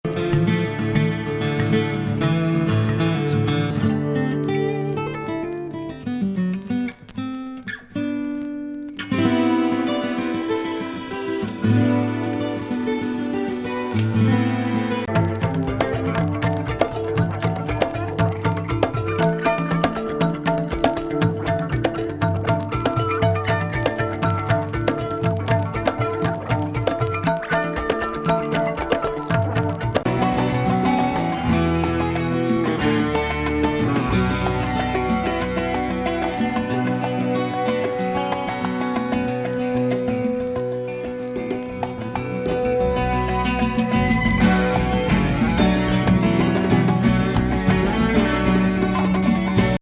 ギターにマンドリン、カリンバにコンガ、
タブラ等が緻密に絡まり入り乱れる最極上アチラ側万華鏡サウンド！！！